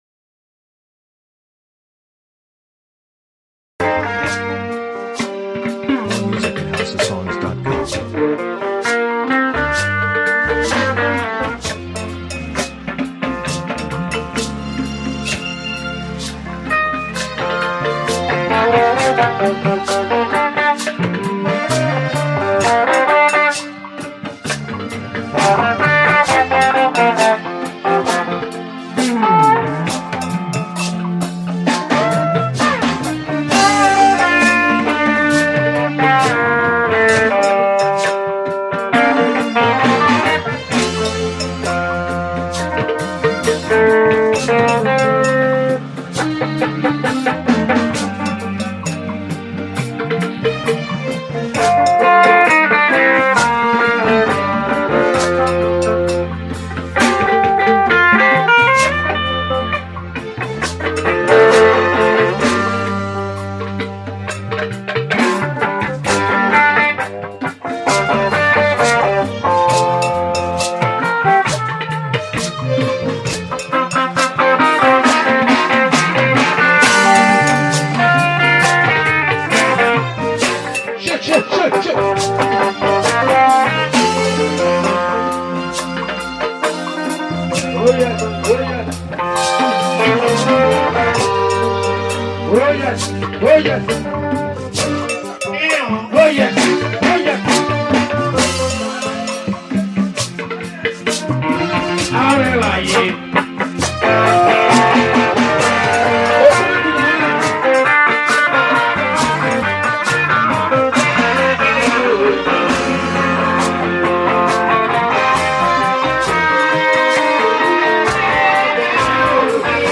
Edo Highlife songs